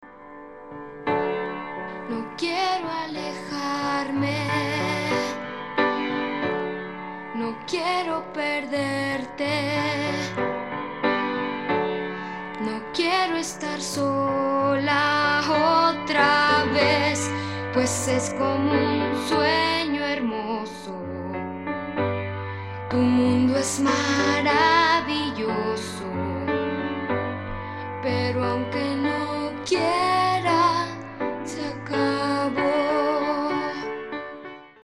rock opera
guitar
drums
bass